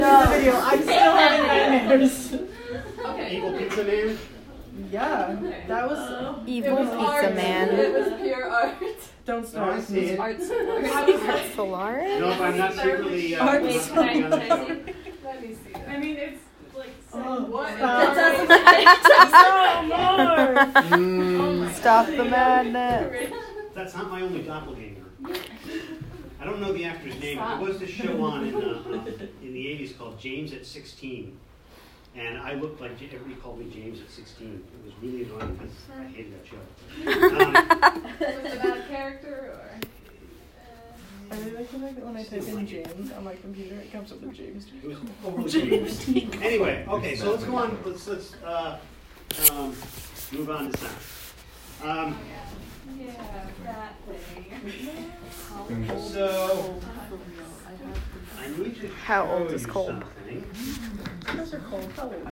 Field Recording
Bits and Bytes, 9 am, 3/14/18 Laughter, chatter, people walking, distant typing behind, a drink getting picked up and set down